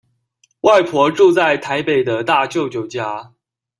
Голоса - Тайваньский 119